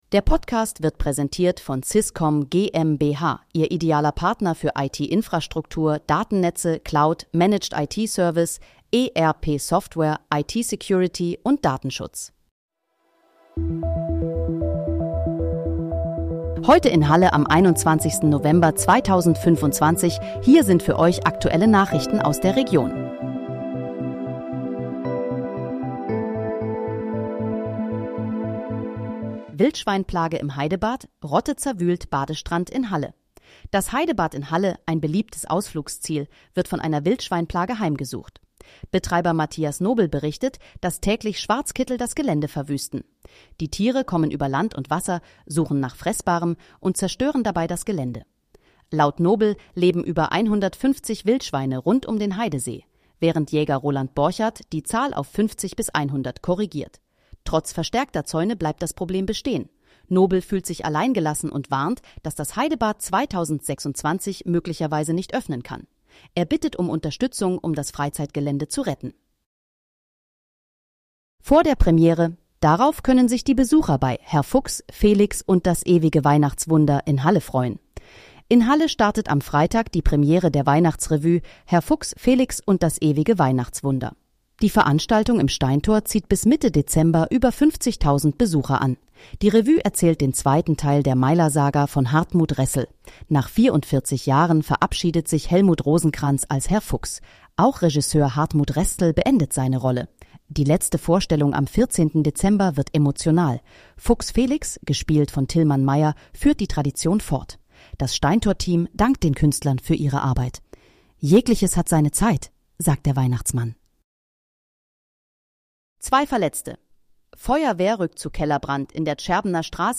Heute in, Halle: Aktuelle Nachrichten vom 21.11.2025, erstellt mit KI-Unterstützung
Nachrichten